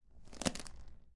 描述：这是三名20多岁的年轻人在观塘传统工业区的香港咖啡馆聊天的录音。时间是早餐时间，大约9:30。这种语言是真实的，真正的香港广东话是由那个年龄段的人使用的。
标签： 七赞廷香港 香港 咖啡店 早餐 竞技场 - 的 - 勇气 青少年 聊天
声道立体声